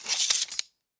Sword Sheath 2.ogg